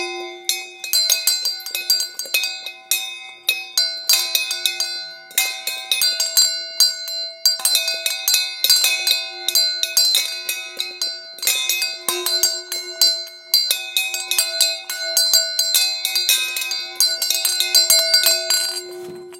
Kovové zvončeky s vtáčikom